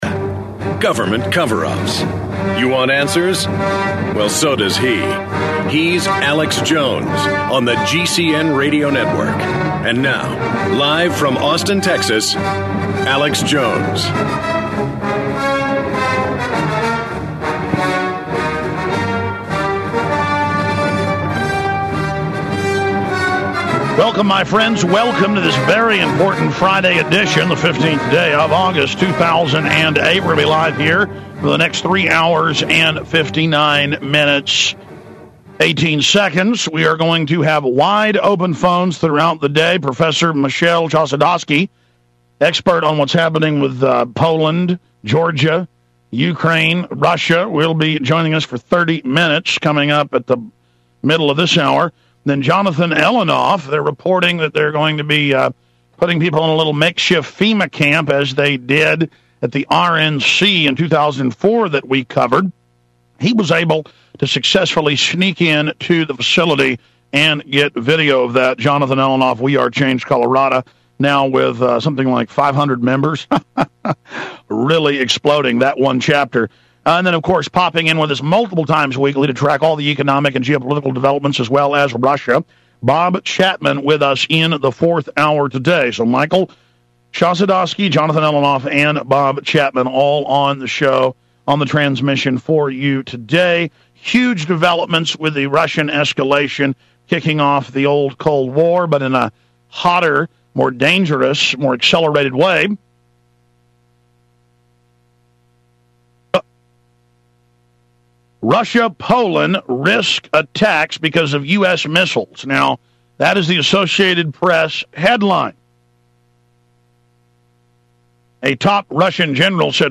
Watch Alex's live TV/Radio broadcast.